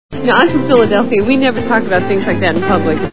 Manhattan Movie Sound Bites